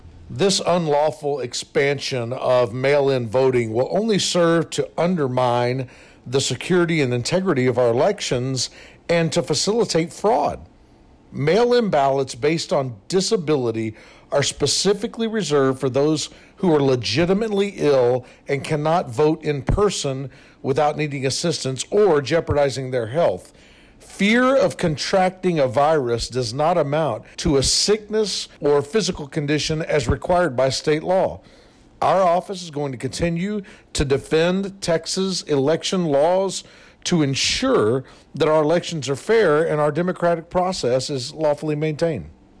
PAXTON-FULL-STATEMENT-1.wav